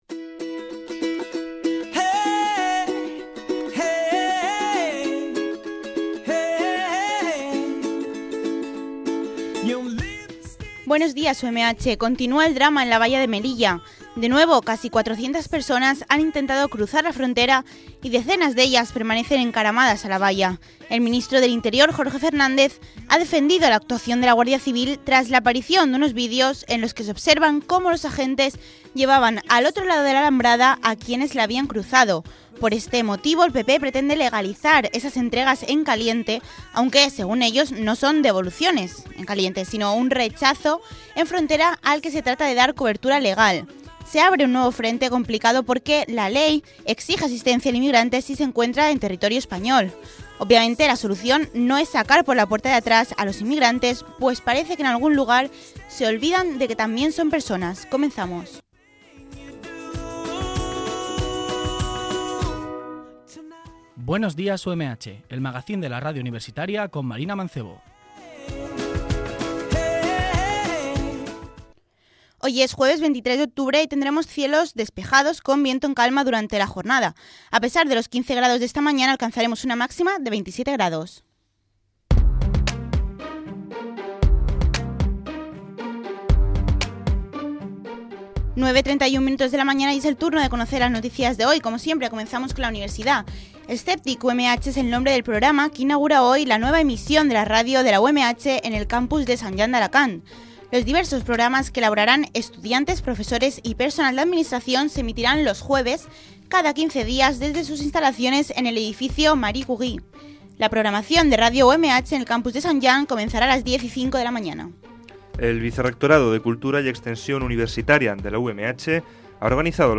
Magacín diario “Buenos días UMH” que incluye noticias de la actualidad informativa y secciones elaboradas por estudiantes de Periodismo.